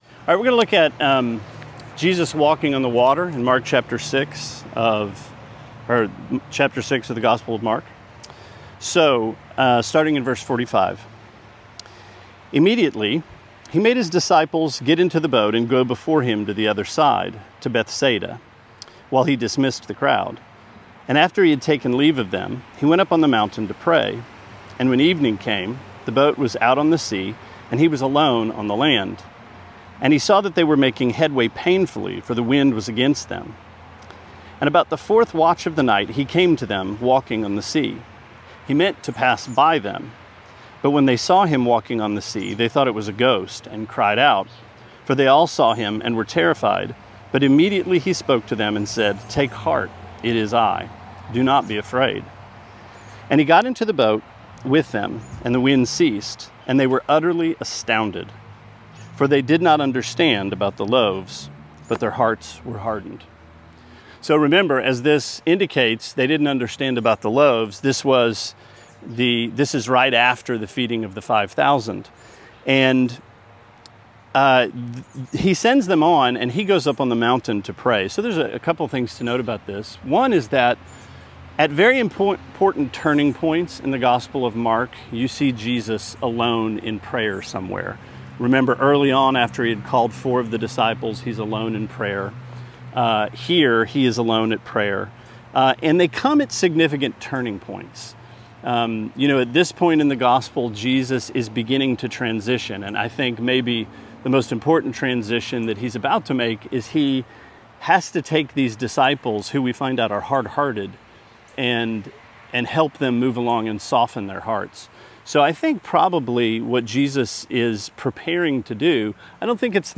Sermonette B 7/6: Mark 6:45-52: Sinai at Sea